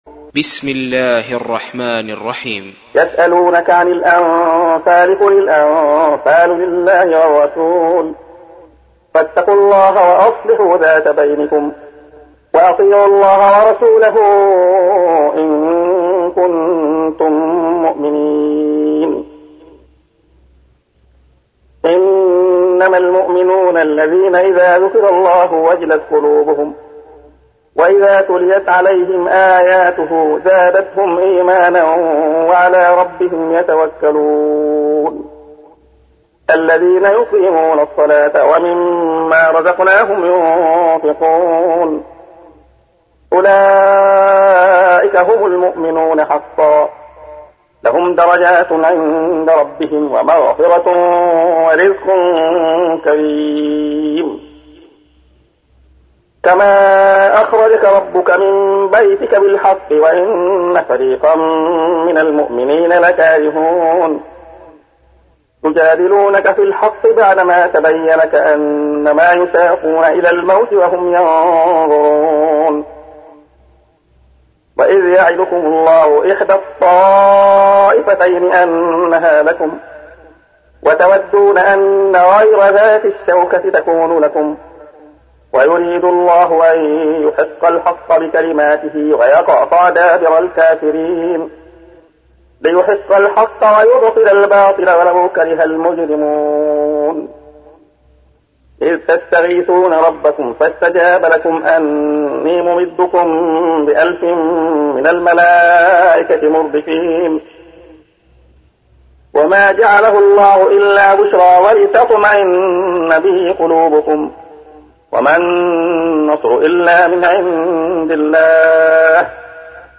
تحميل : 8. سورة الأنفال / القارئ عبد الله خياط / القرآن الكريم / موقع يا حسين